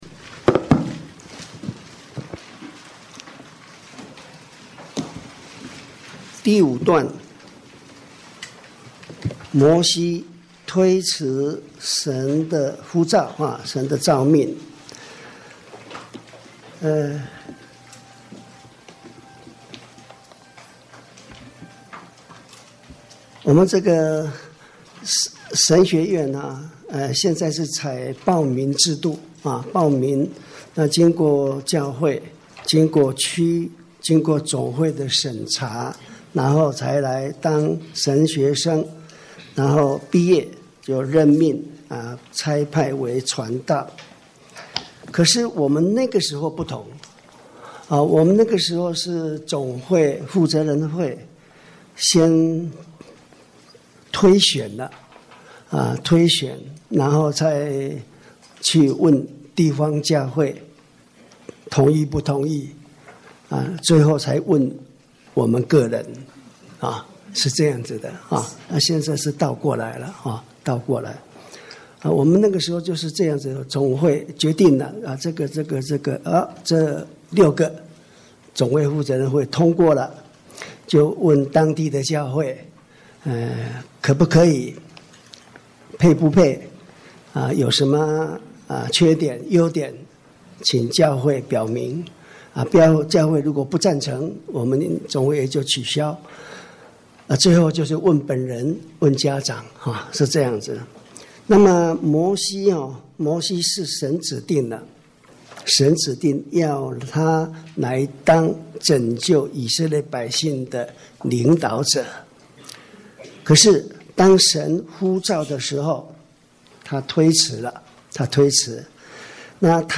講習會